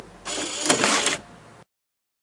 DVDEject2单声道夹子
描述：DVD播放器弹出磁盘。
Tag: 播放器 弹出磁盘 DVD